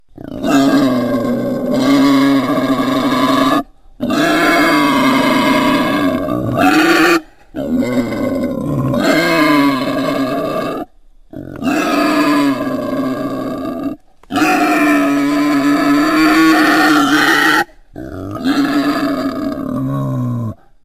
На этой странице собраны натуральные звуки диких кабанов: от хрюканья и рычания до топота копыт по лесу.
Рычание разъяренного кабана